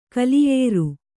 ♪ kaliyēru